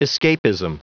Prononciation du mot escapism en anglais (fichier audio)
Prononciation du mot : escapism